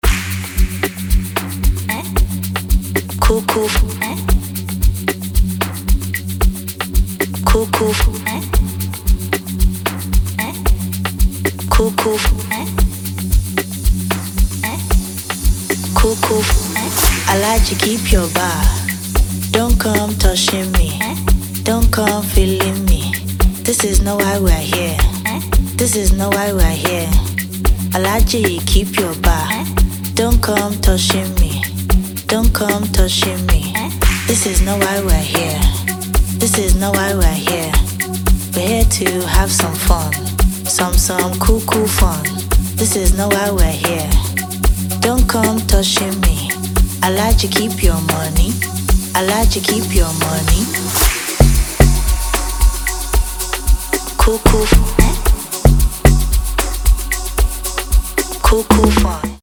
American DJ trio
dance track
amapiano track